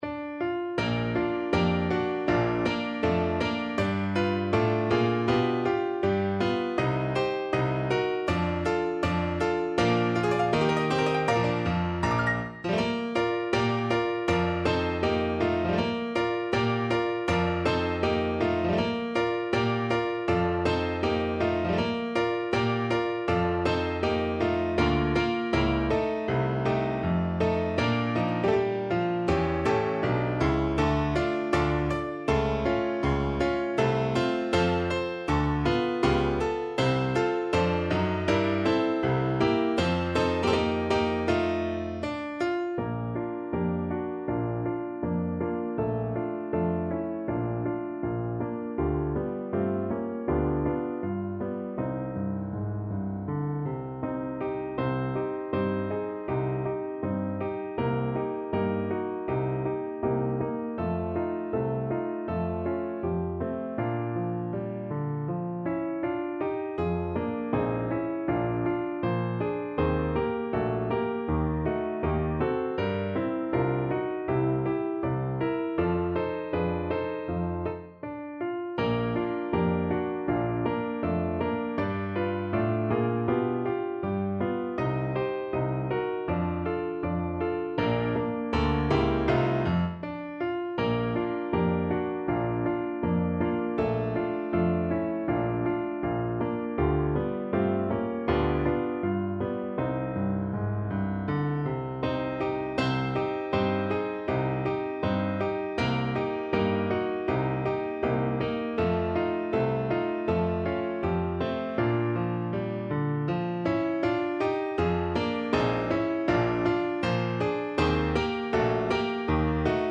No parts available for this pieces as it is for solo piano.
2/2 (View more 2/2 Music)
Moderato =80
Piano  (View more Easy Piano Music)
Pop (View more Pop Piano Music)